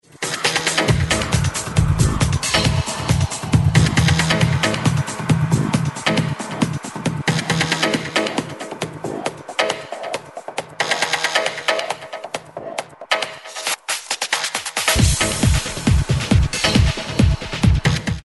A well known tune in a new remix.